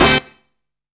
SO BAD HIT.wav